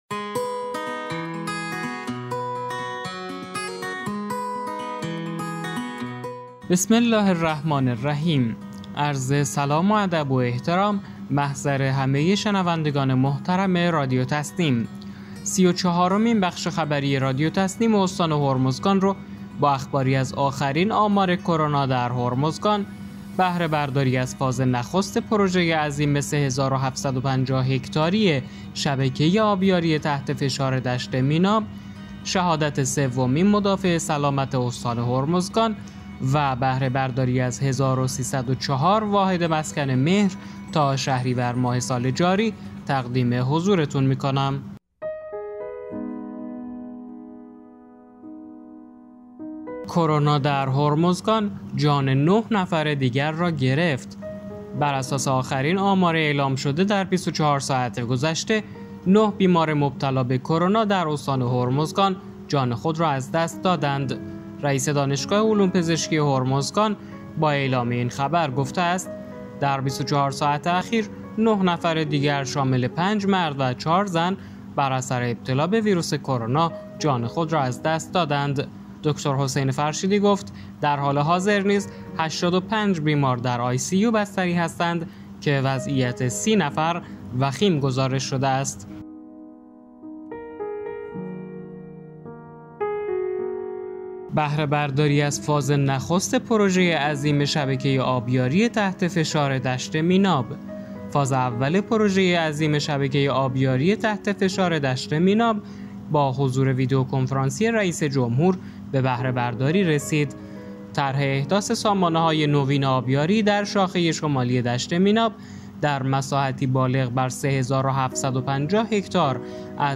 به گزارش خبرگزاری تسنیم از بندرعباس، سی‌ و چهارمین بخش خبری رادیو تسنیم استان هرمزگان با اخباری از آخرین آمار کرونا در هرمزگان، بهره‌برداری از فاز نخست پروژه عظیم 3750 هکتاری شبکه آبیاری تحت فشار دشت میناب، شهادت سومین مدافع سلامت استان هرمزگان و بهره‌برداری از 1304 واحد مسکن مهر تا شهریور ماه سال جاری منتشر شد.